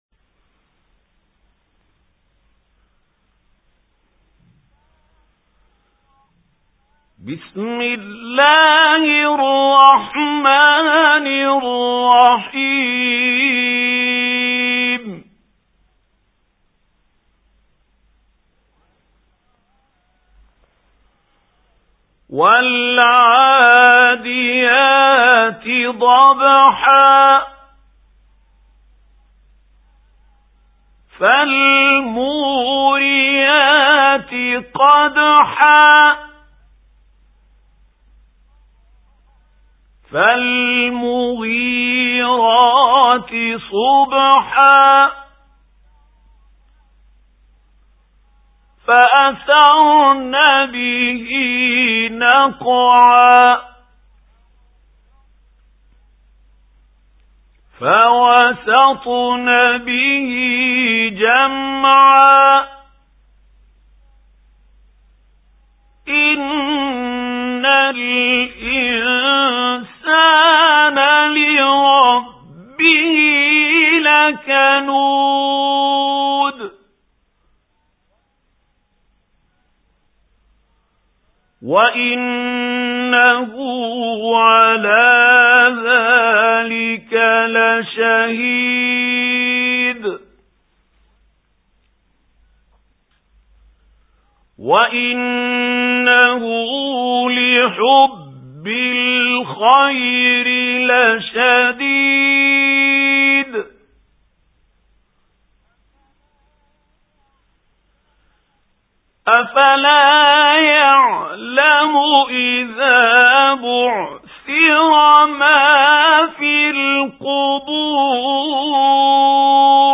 سُورَةُ العَادِيَاتِ بصوت الشيخ محمود خليل الحصري